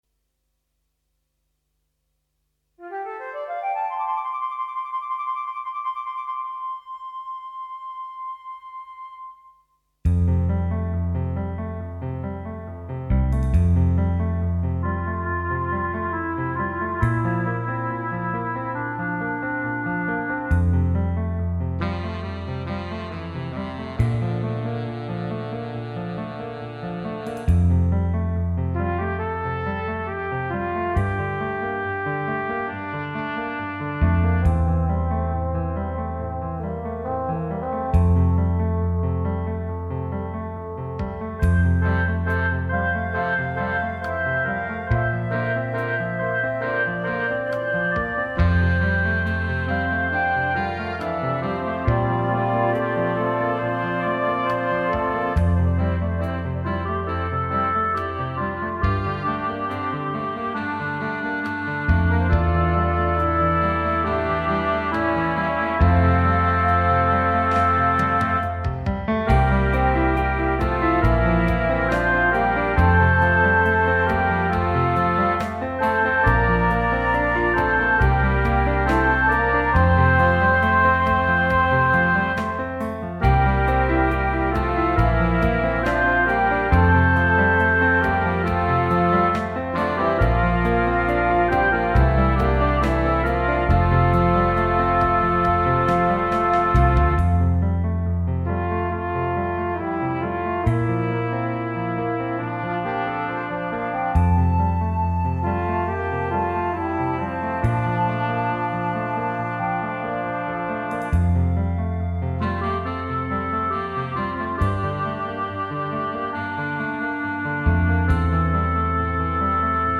Instrumentation: C, Bb, Eb, pno, Bass, Drums / perc.
Scored for 8 part flexible ensemble